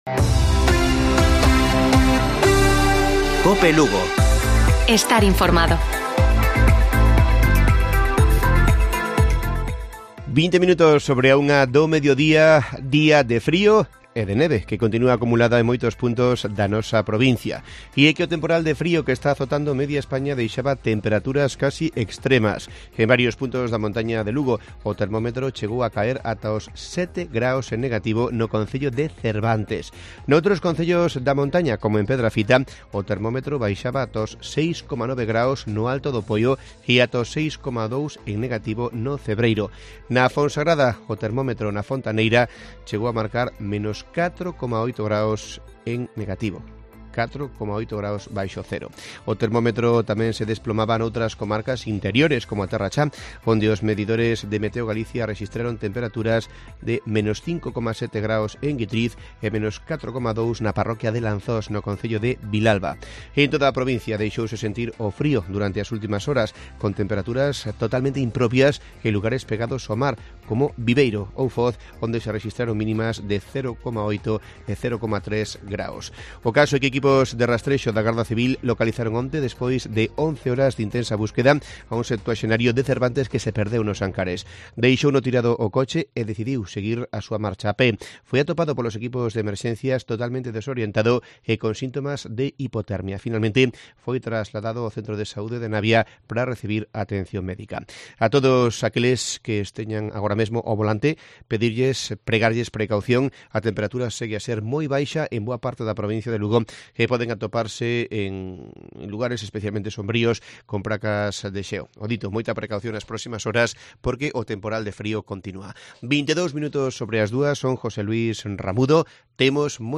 Informativo Mediodía de Cope Lugo. 07 de enero. 14:20 horas